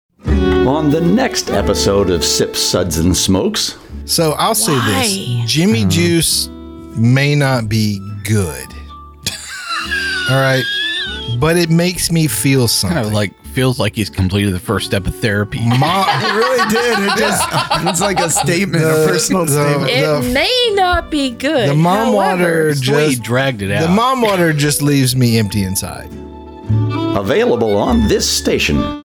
< Version: 1 Episode 637 Version Description: Radio MP3 with music beds Version Length: 12:43 a.m. Date Recorded: Aug. 25, 2025 1: 12:43 a.m. - MB download 2: midnight - MB download